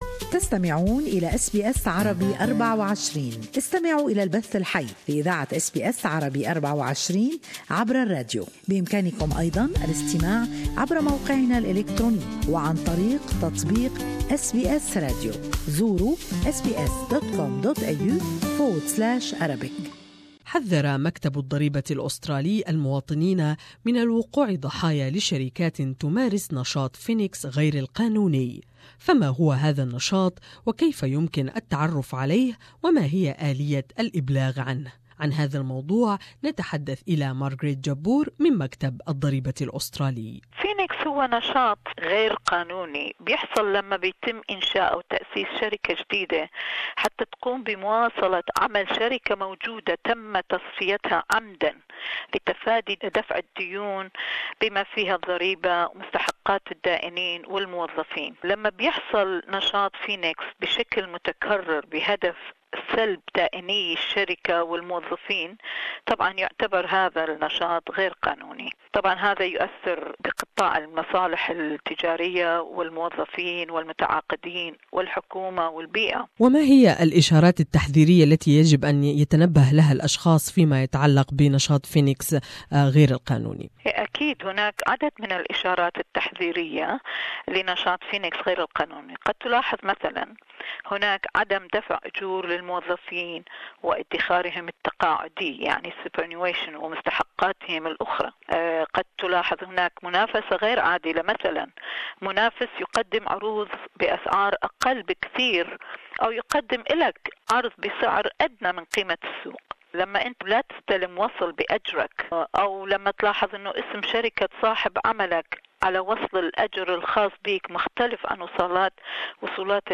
The ATO has warned about Phoenix activities and urged the community to report any suspesious behavior More in this interview